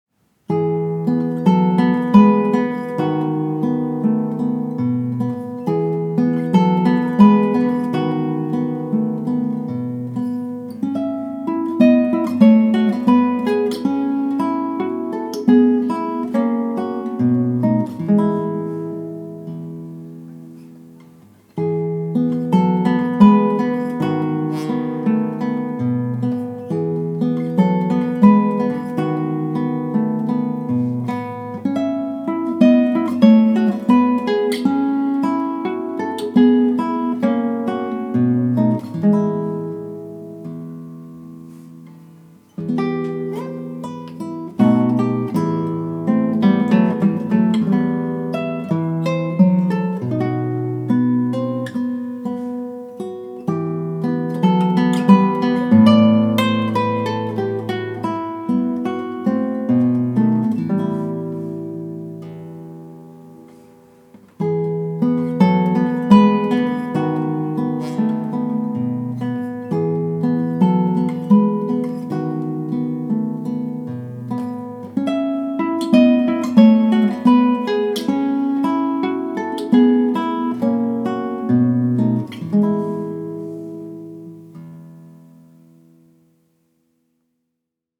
Aufnahme klassische Gitarre
Zusammen mit dem zugemischten Hall über den Send-Weg klingt das Ganze schon zufriedenstellend... etwas störend wäre jetzt noch das erhöhte Grundrauschen... jetzt werde ich mich noch etwas mit dem EQ auseinandersetzen...
Ich habe es mal durch einen Kompressor gejagt und Hall draufgelegt.